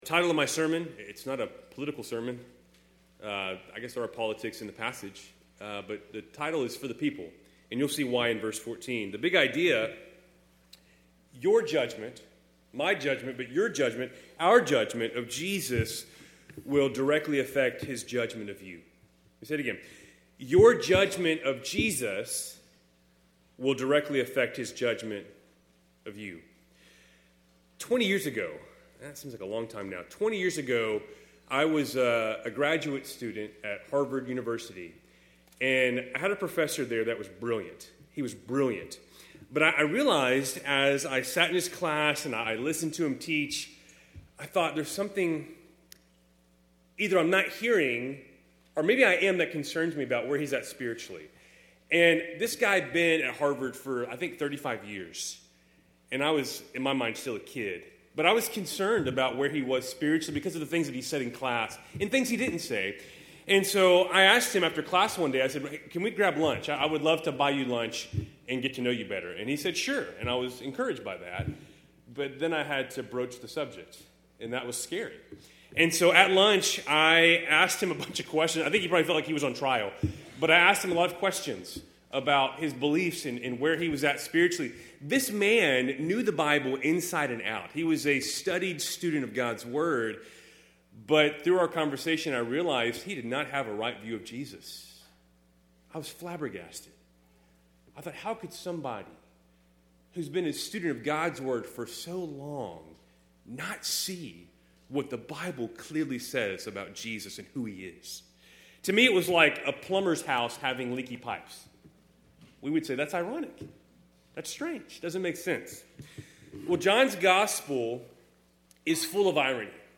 Keltys Worship Service, January 11, 2026